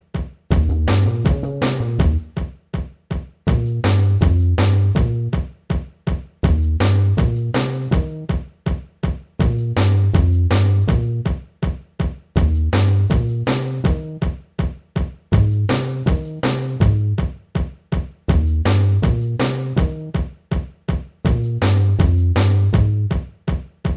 Minus Guitars Pop (1960s) 2:27 Buy £1.50